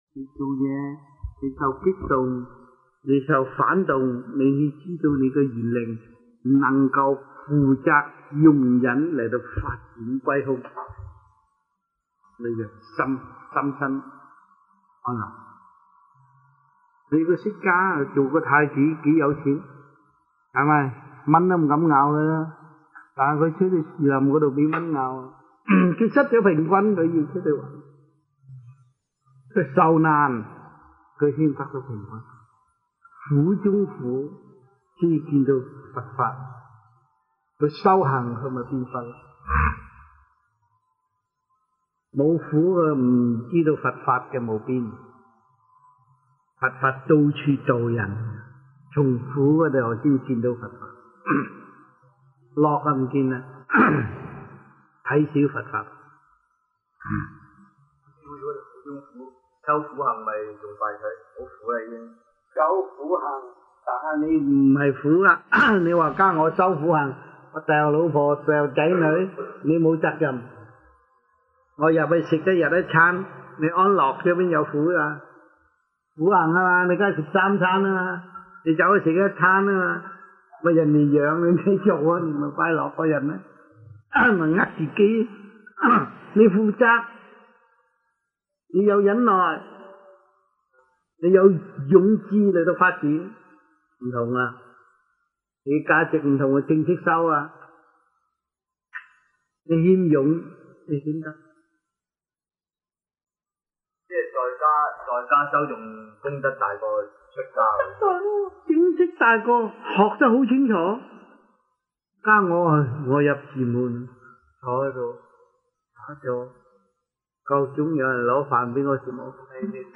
Q&A in Chinese-1988 (中文問答題)